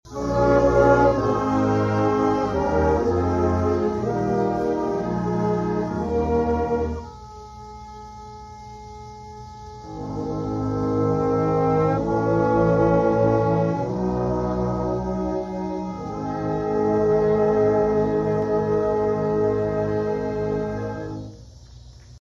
summer concert
The Emporia Municipal Band’s summer concert season is over.